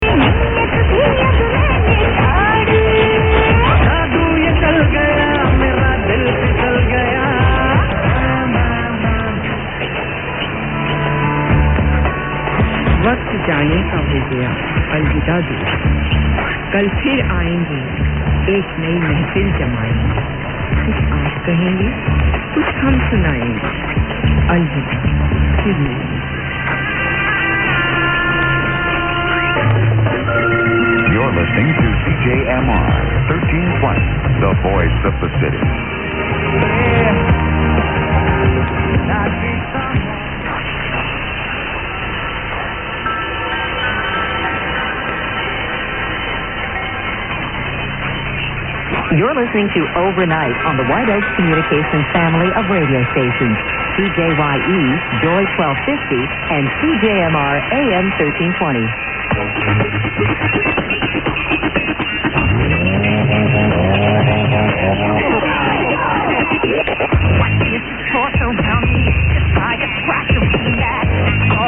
After some attempts at recording at dawn, and hearing virtually nothing, despite the beverage antennas, signals are again starting to re-appear as if by magic.